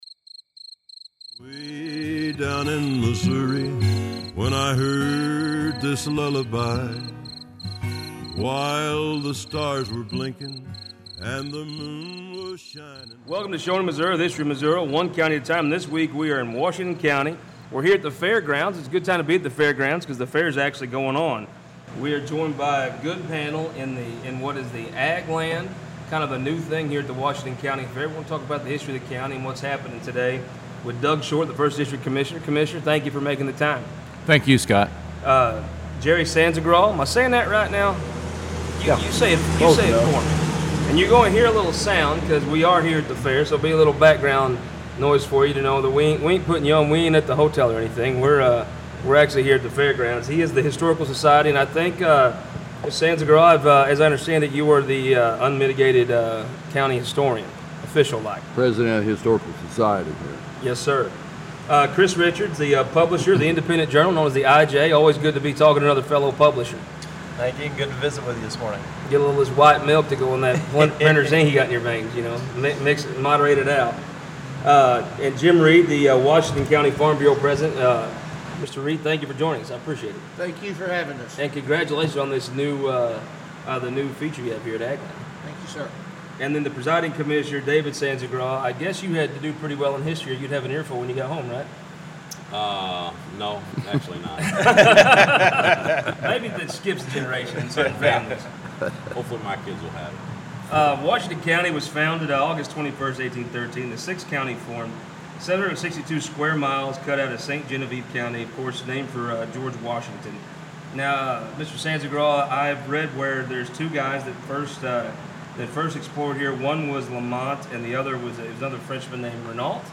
This week, the show traveled to the Washington County Fair in Potosi, MO; county seat of Washington County.